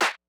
27 clap hit.wav